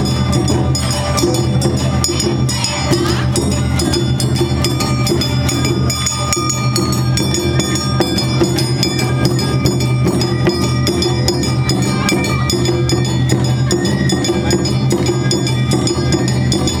−　阿波踊り　−
街中ではどこからともなく阿波踊りの音楽が